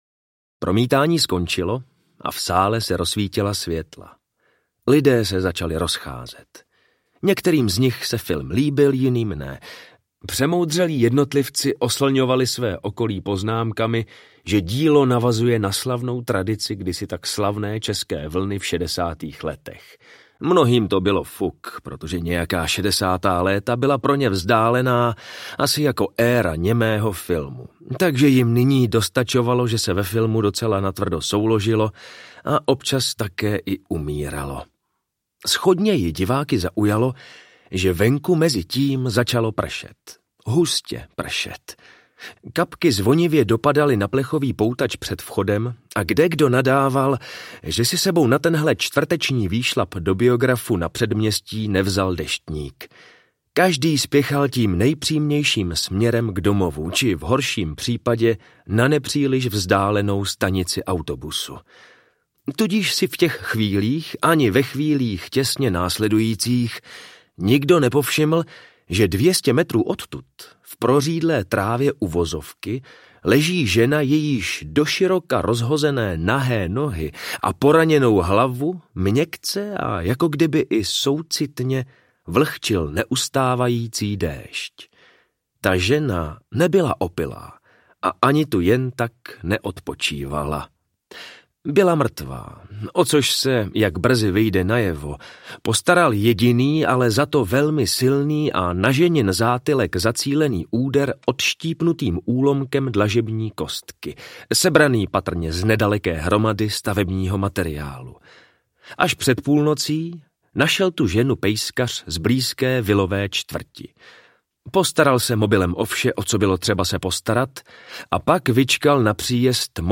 Umírání na splátky audiokniha
Ukázka z knihy
Vyrobilo studio Soundguru.